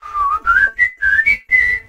darryl_start_vo_07.ogg